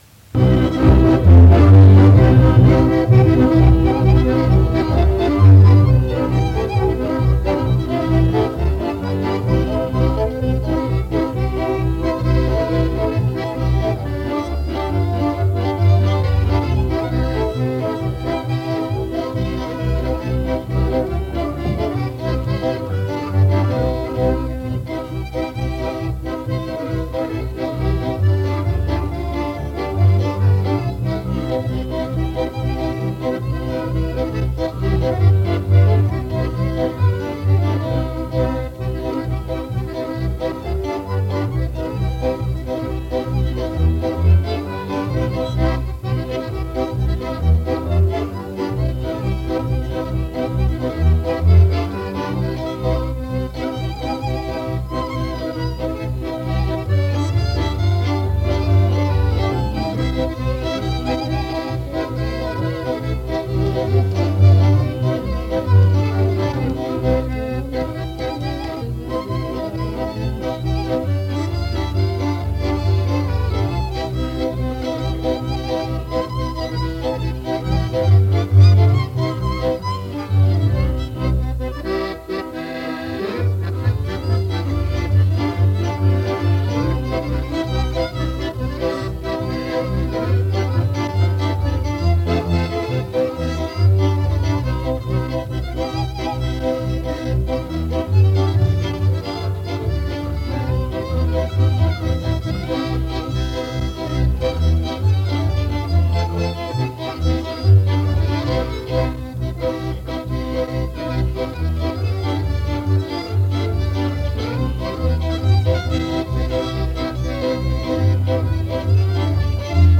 Instrumentalny 4 – Żeńska Kapela Ludowa Zagłębianki
Nagranie archiwalne